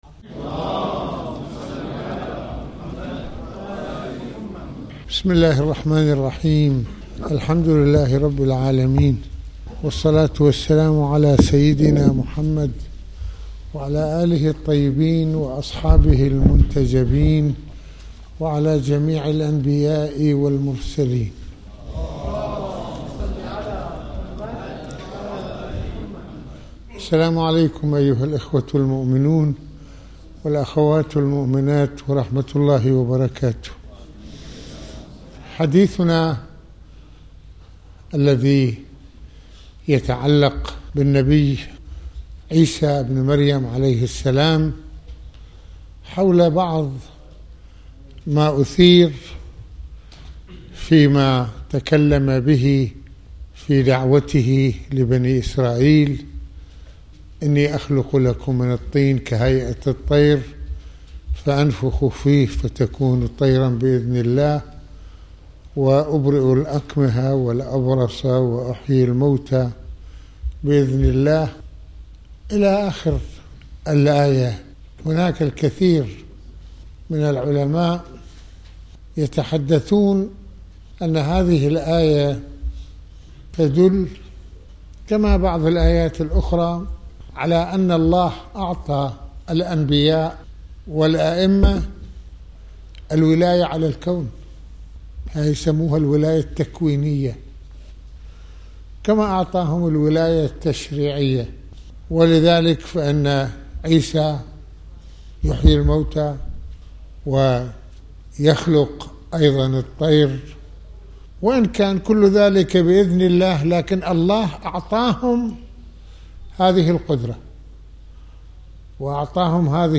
هل فكرة الولاية التكوينية هي غلو وكفر | محاضرات
المناسبة : ندوة السبت المكان : الشام - السيدة زينب(ع)